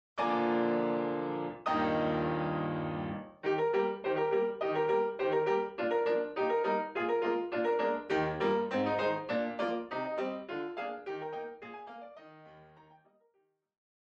piano
2/4 – 32 bars